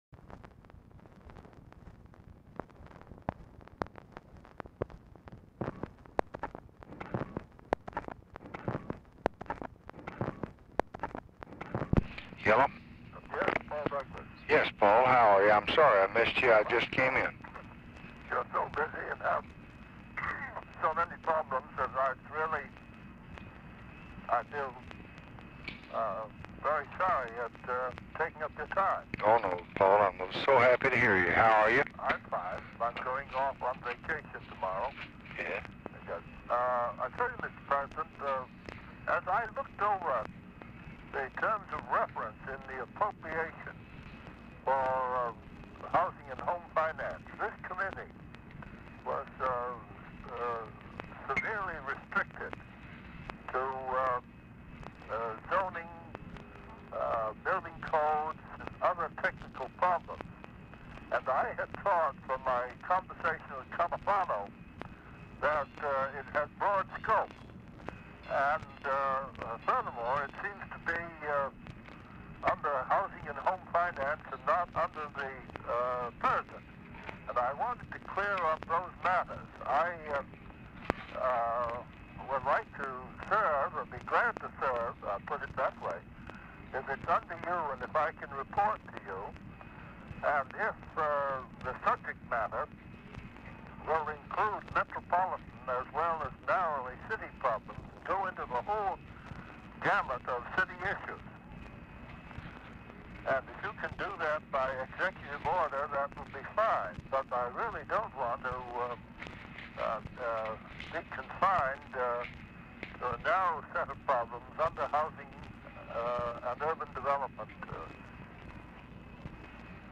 Telephone conversation # 11191, sound recording, LBJ and PAUL DOUGLAS, 12/22/1966, 9:00PM | Discover LBJ
Format Dictation belt
Location Of Speaker 1 LBJ Ranch, near Stonewall, Texas